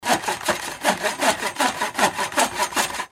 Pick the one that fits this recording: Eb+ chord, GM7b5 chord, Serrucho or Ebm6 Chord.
Serrucho